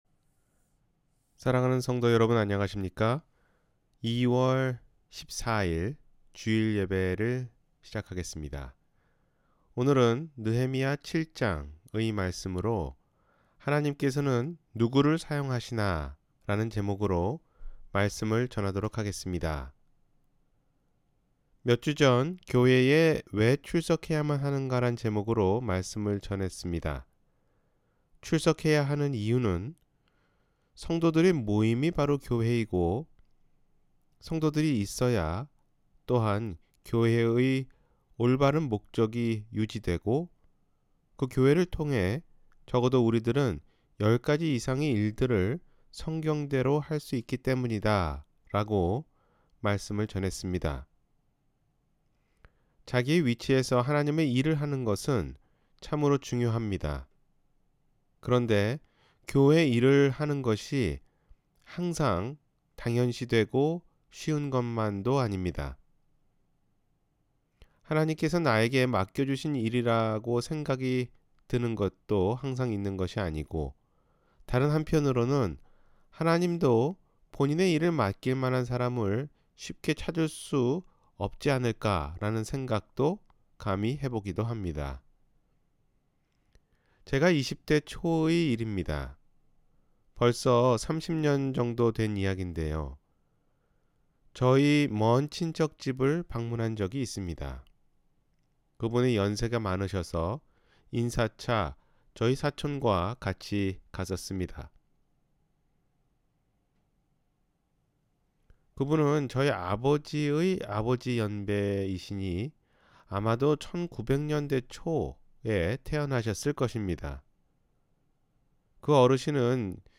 하나님께서는 누구를 사용하시나 (느헤미야 7장)- 주일설교